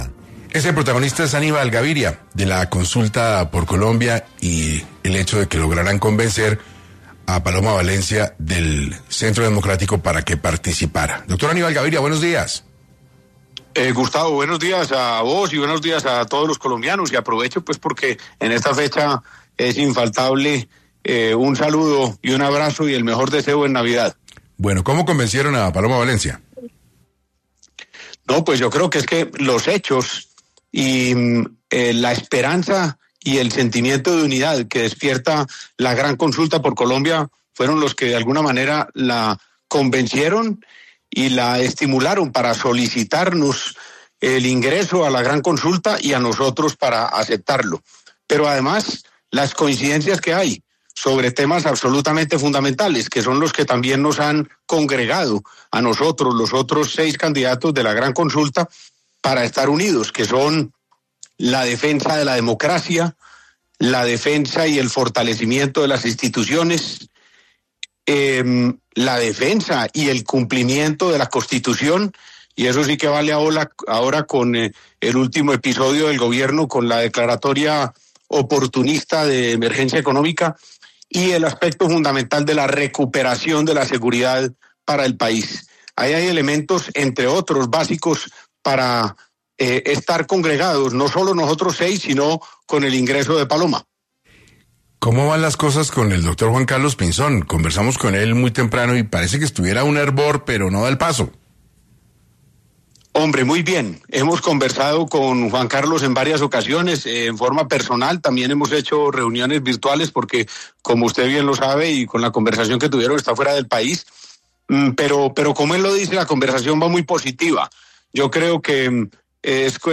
Aníbal Gaviria, precandidato presidencial, estuvo en 6 AM de Caracol Radio hablando sobre la Gran Consulta por Colombia